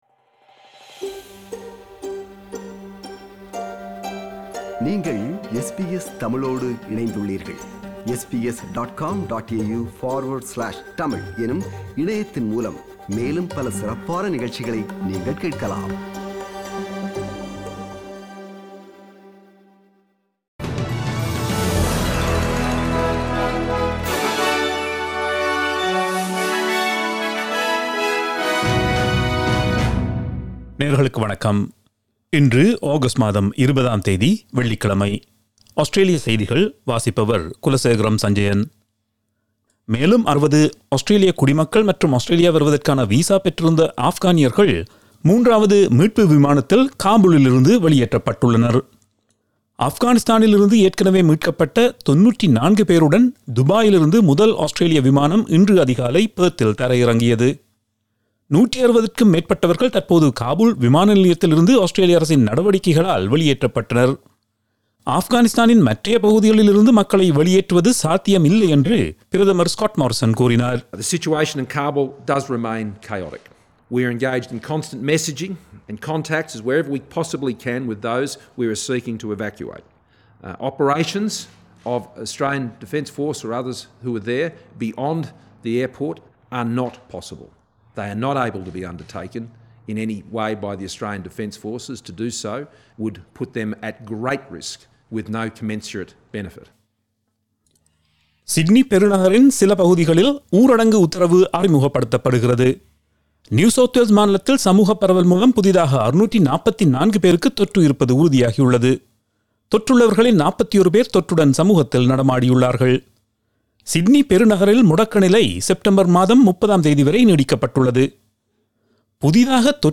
Australian news bulletin for Friday 20 August 2021.